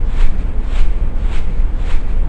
smallerfan.wav